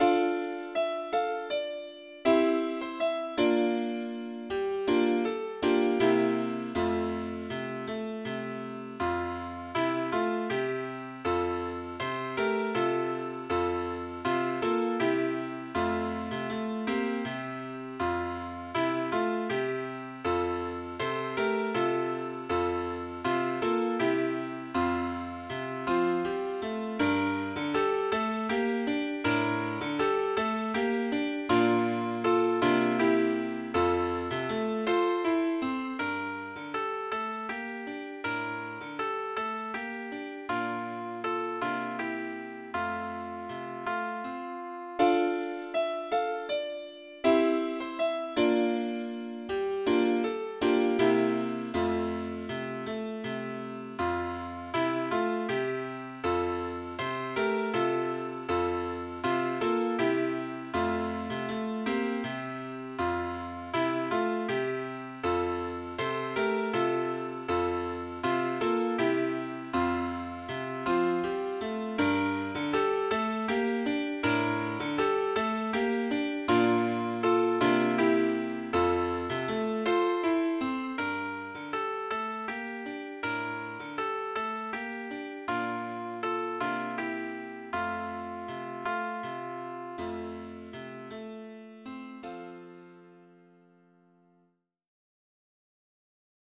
Georgian MID Songs for Children